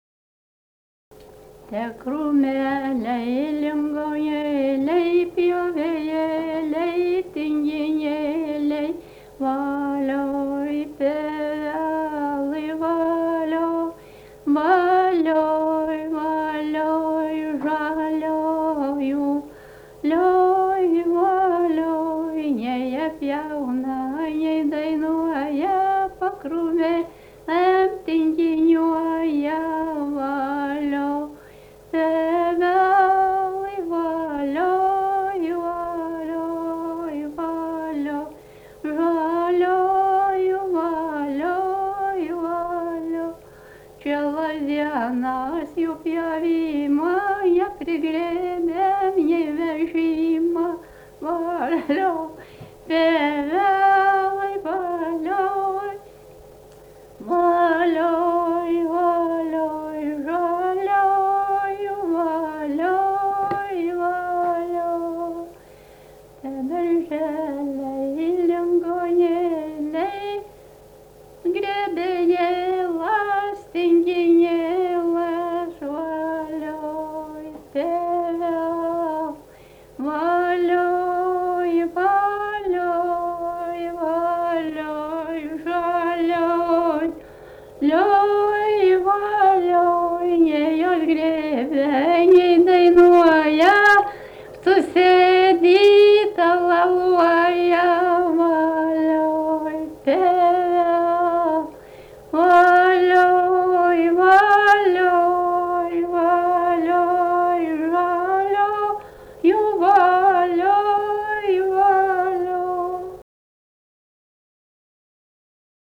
daina, kalendorinių apeigų ir darbo
Čypėnai
vokalinis